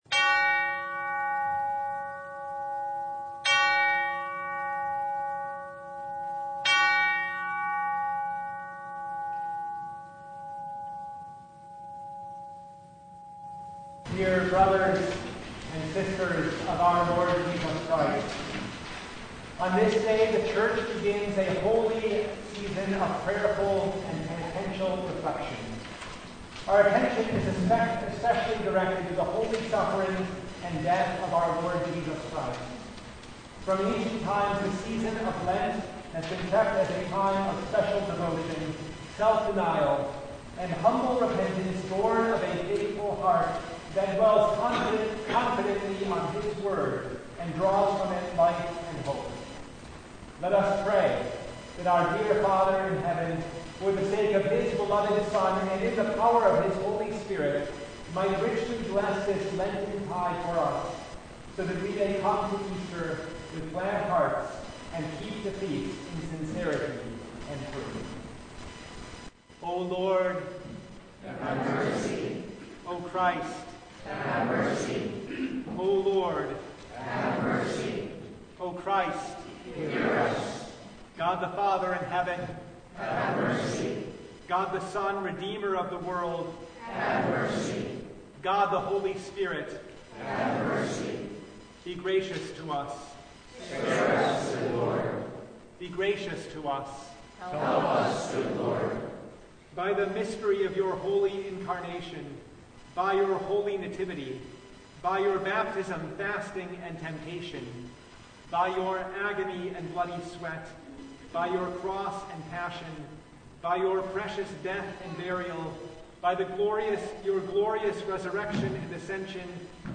Passage: Joel 2:12-19 Service Type: Ash Wednesday
Full Service